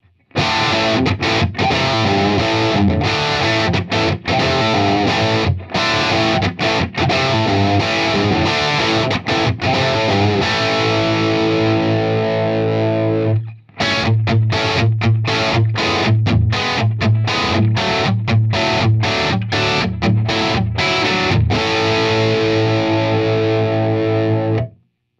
Les Paul (Gibson 57 pickups), into a Republic Amp (My Custom 50watt) into a 1976 Marshall 4x12 with the original Greenback Celestion 25watt speakers.
Audix D1 mic into a Great River MP-2NV preamp with the EQ-2NV EQ (All bands off, HP set to 33Hz) into a 1981 Ashly SC55 into a Apogee AD16 A/D converter.
Room mics are a stereo pair of sE5 mics into my old 8ch Altec mic pre into a Ashly CL-50 compressor, into the Apogee AD-16 A/D converter.